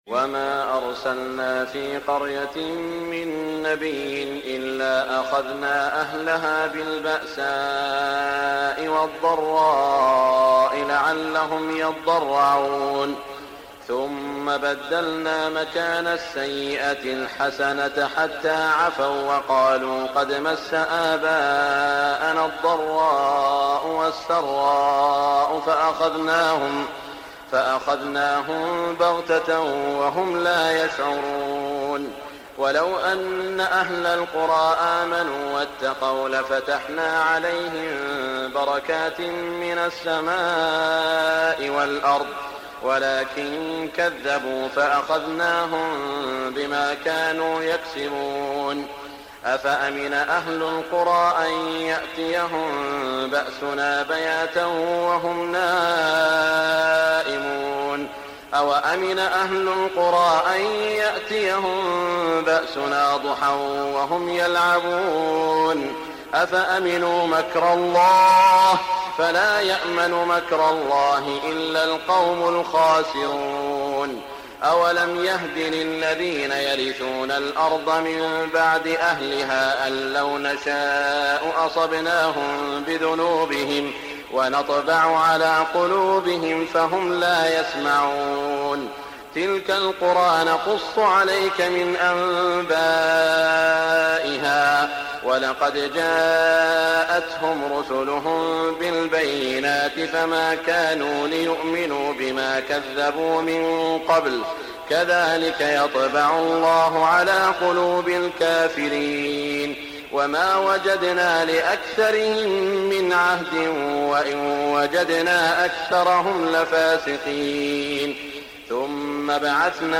تهجد ليلة 29 رمضان 1418هـ من سورة الأعراف (94-188) Tahajjud 29 st night Ramadan 1418H from Surah Al-A’raf > تراويح الحرم المكي عام 1418 🕋 > التراويح - تلاوات الحرمين